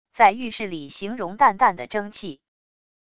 在浴室里形容淡淡的蒸气wav音频